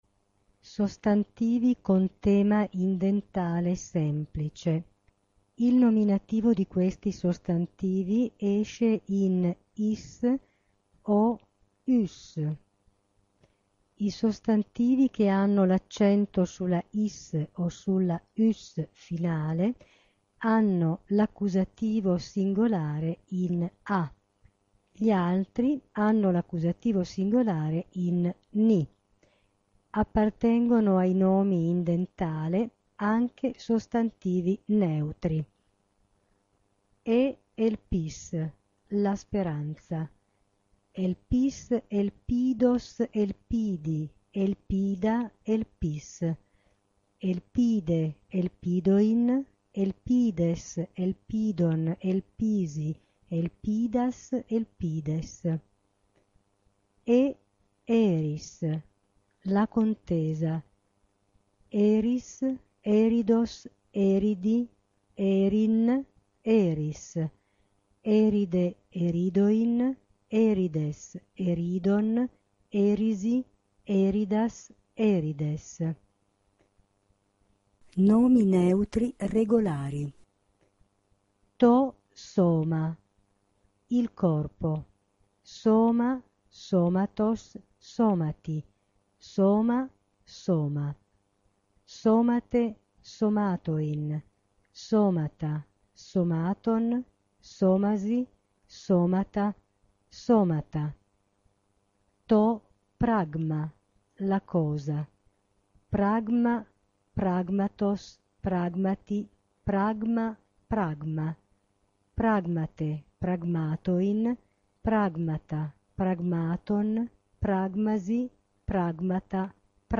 ) permette di sentire la lettura dei sostantivi della terza declinazione in dentale